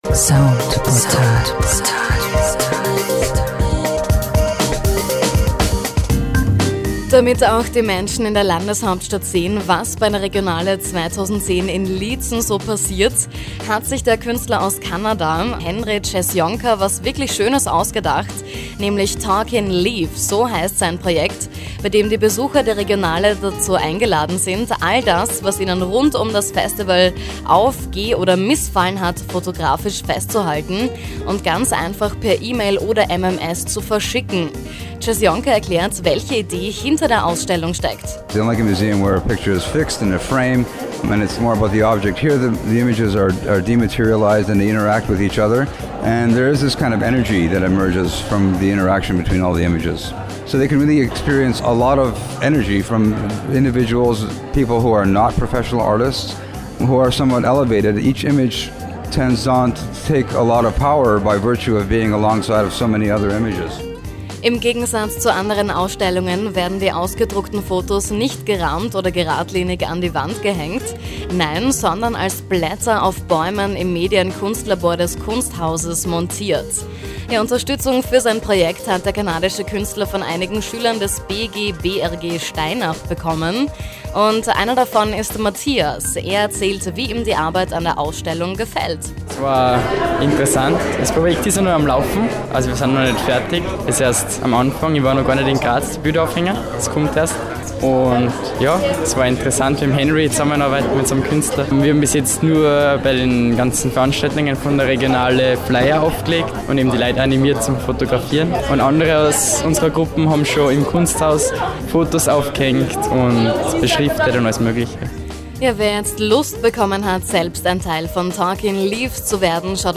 Einige Impressionen + ein kleiner Beitrag von Radio Soundportal.
Radiobeitrag